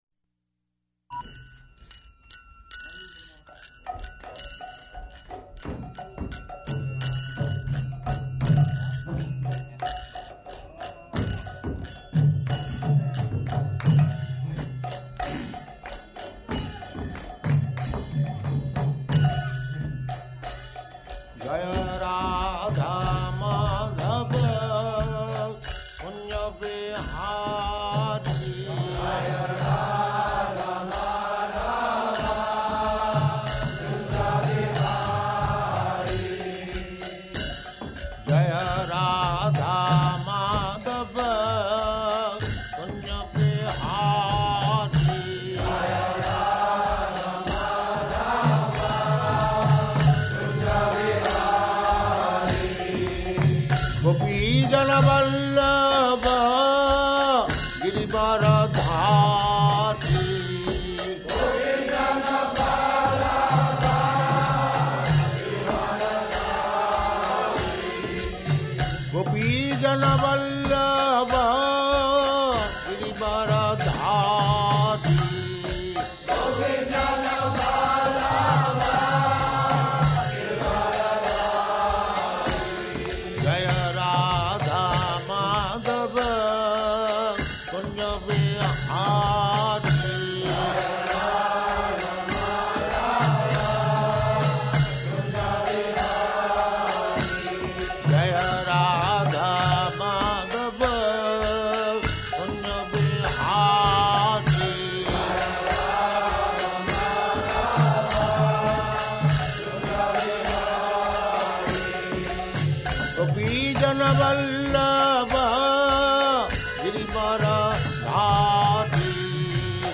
Location: Los Angeles
[devotees chant verse; Prabhupada corrects pronunciation of a number of devotees]